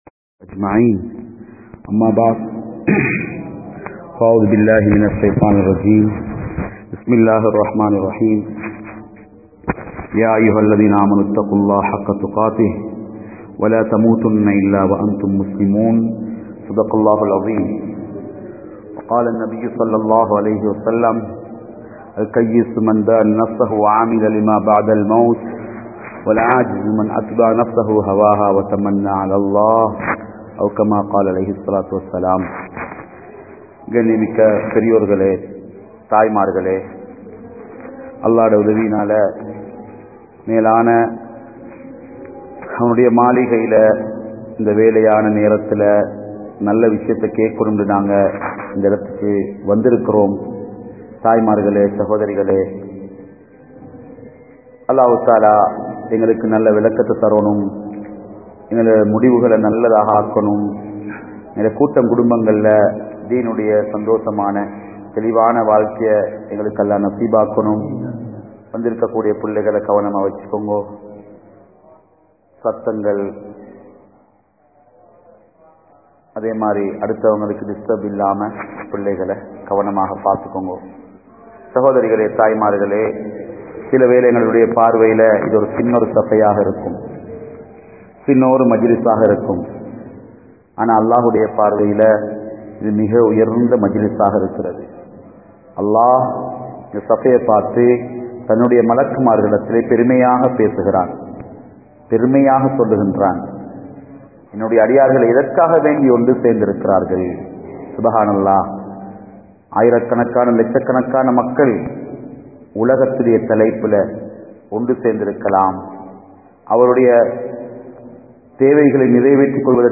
Pengale! Maranaththai Maranthu Vidaatheerhal (பெண்களே! மரணத்தை மறந்து விடாதீர்கள்) | Audio Bayans | All Ceylon Muslim Youth Community | Addalaichenai
Ladies Bayan